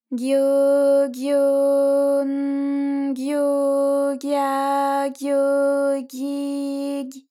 ALYS-DB-001-JPN - First Japanese UTAU vocal library of ALYS.
gyo_gyo_n_gyo_gya_gyo_gyi_gy.wav